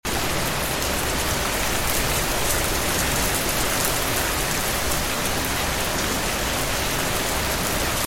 Calming rain with fireplace in sound effects free download
Calming rain with fireplace in a cozy living room